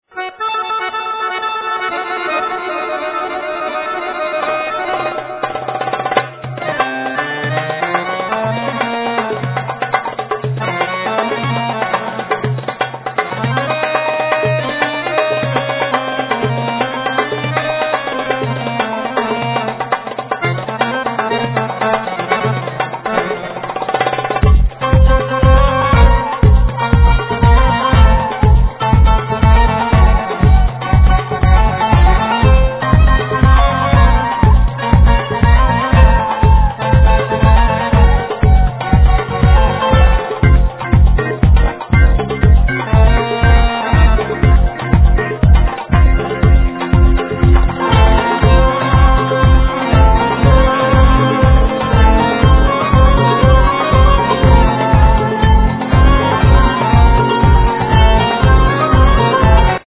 Voice, Vocals, Percussions
Kementse, Violin
Clarinet, Zournas, Ney
Tzouras, Baglamas, Keyboards